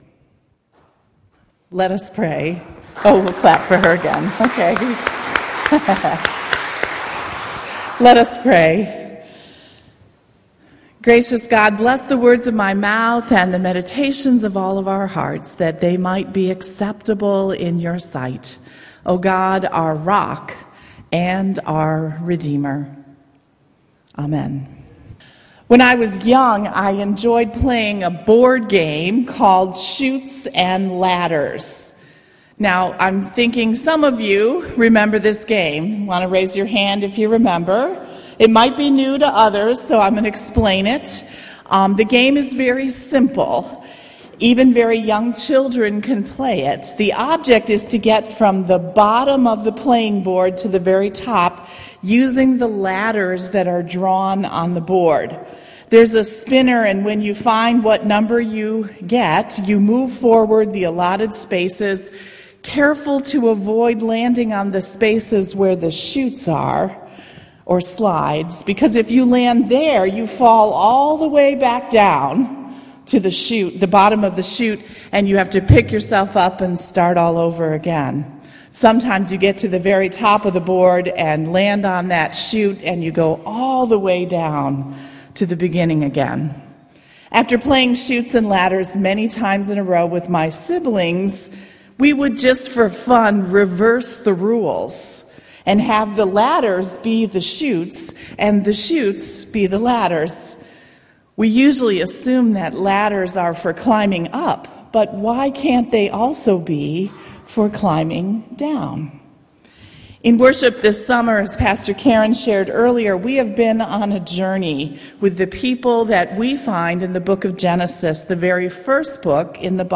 August-19th-Sermon.mp3